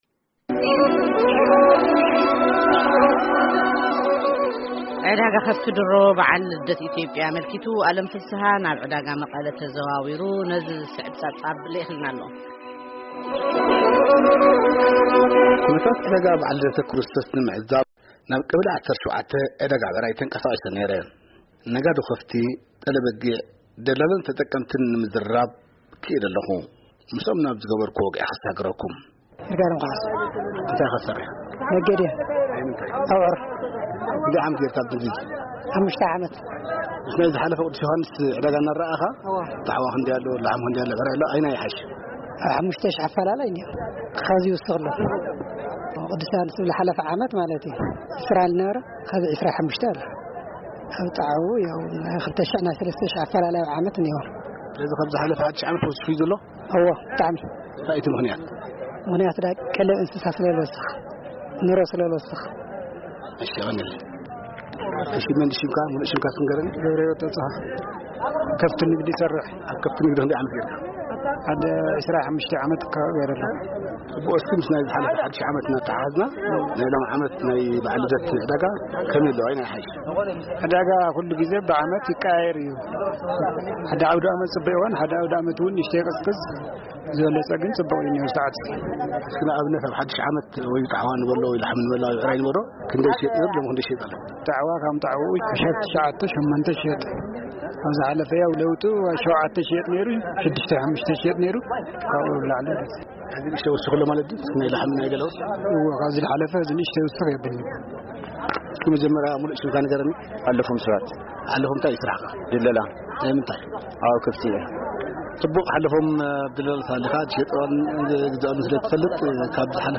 ዕዳጋ ከብቲ ከተማ መቐለ ኣብ በዓል ልደት
ኩነታት ዕዳጋ ከብቲ ኣብ በዓል ልደተ ክርስቶስ ንምዕዛብ ናብ ዕዳጋ ከተማ መቐለ ቀበሌ 17 ብምንቅስቃስ ምስ ነጋዶ ከብቲ፥ ጠለ በጊዕ : ደላሎን ተጠቀምትን ኣዘራሪብና ኣለና።